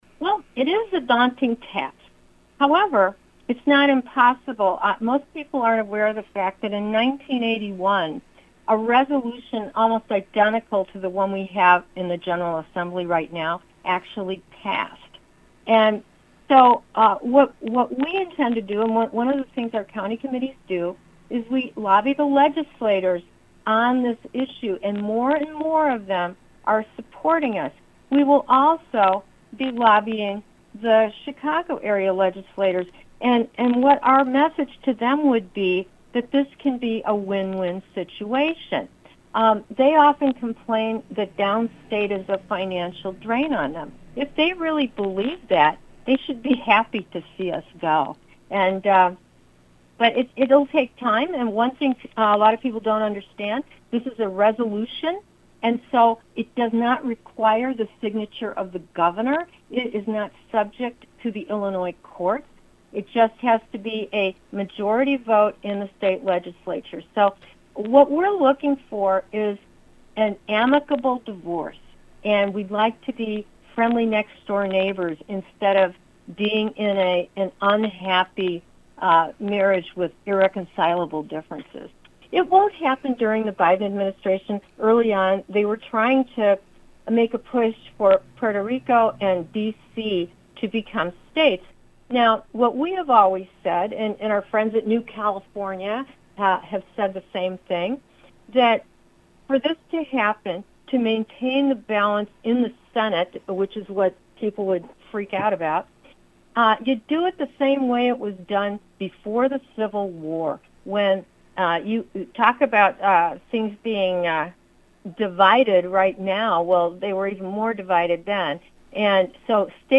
new-il-interview-part-5.mp3